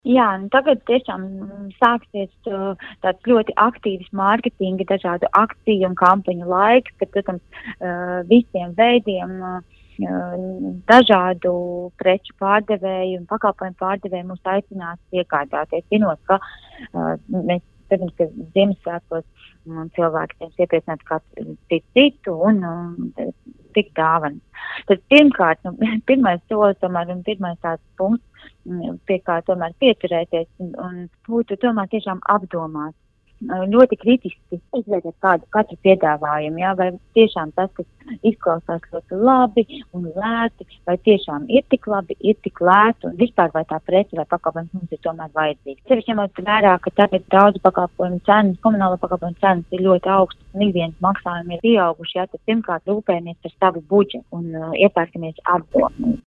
RADIO SKONTO Ziņās par iepirkšanos pirms svētkiem